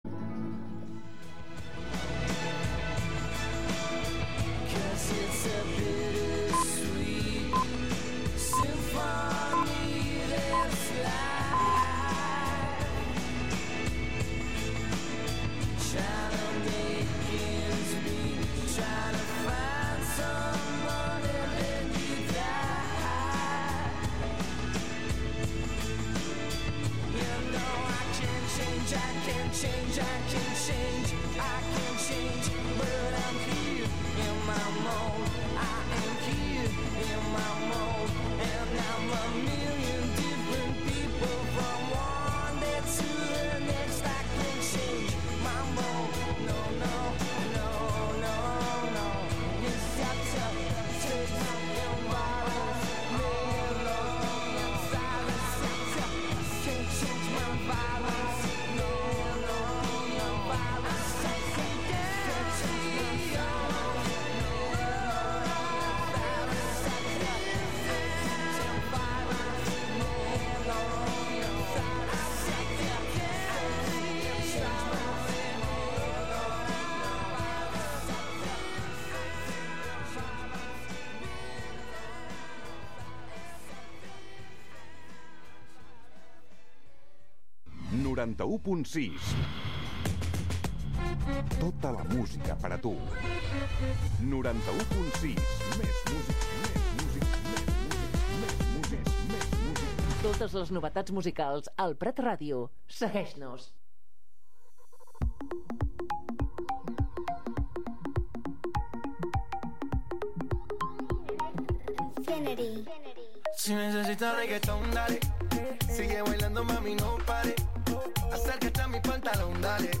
Recorreguts musicals pels racons del planeta, música amb arrels i de fusió.